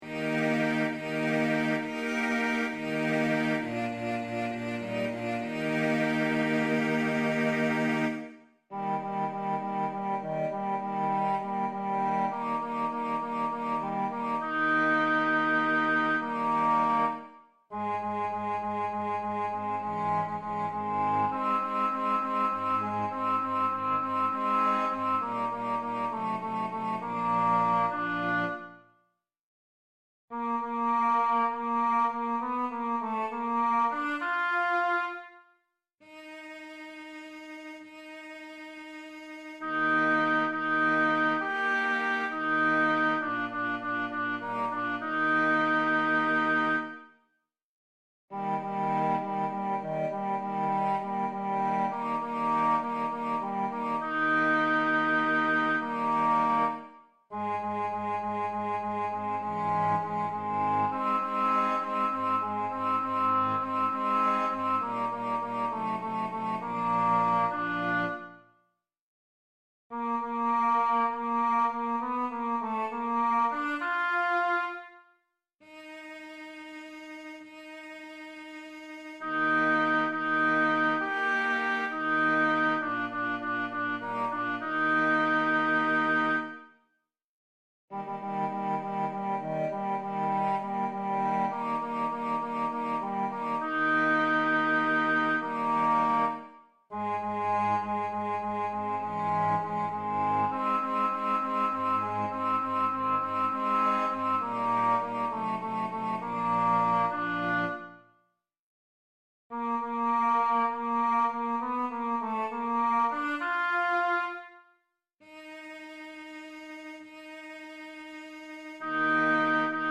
Lützows wilde Jagd Tenor 2 als Mp3
luetzows-wilde-jagd-einstudierung-tenor-2.mp3